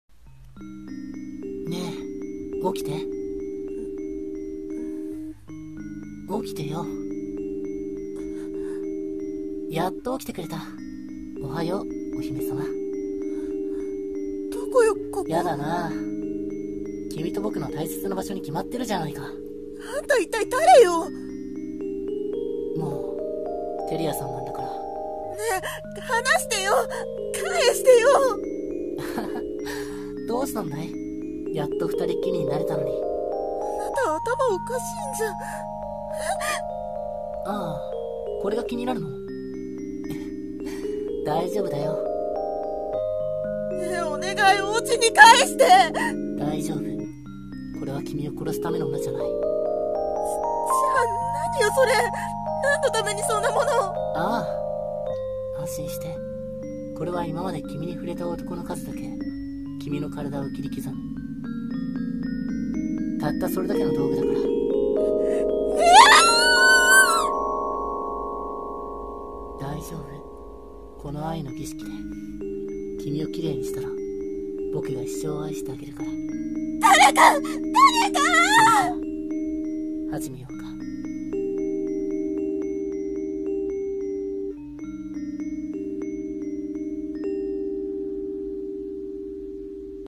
【二人用声劇台本】歪んだ愛の儀式